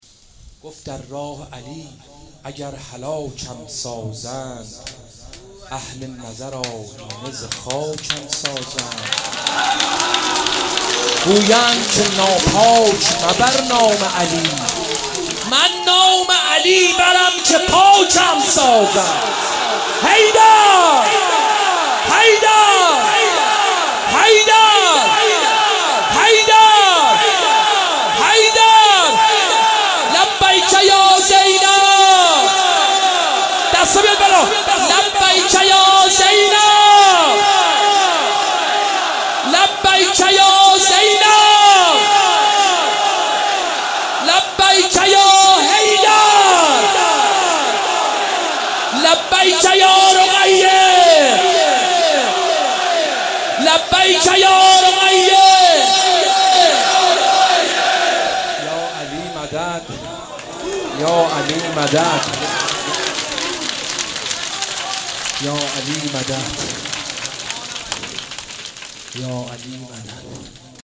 مناسبت : شب نوزدهم رمضان - شب قدر اول
قالب : مدح